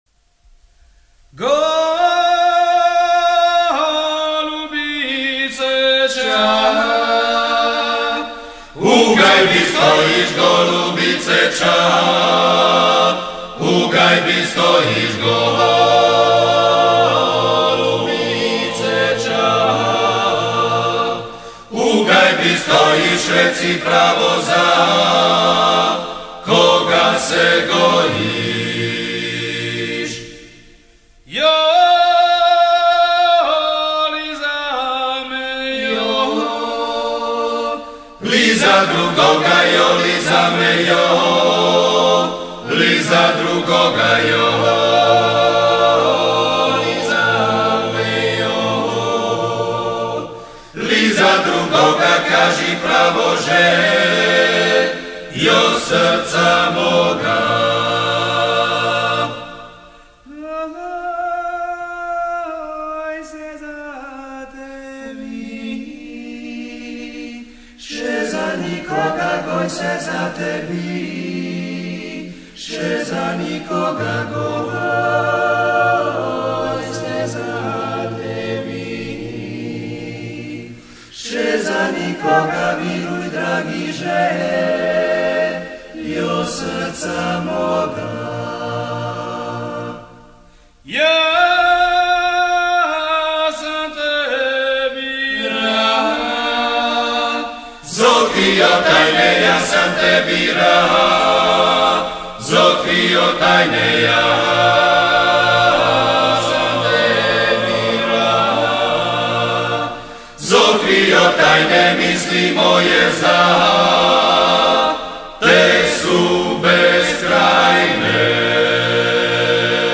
I. Tenor
Bariton
I. Bas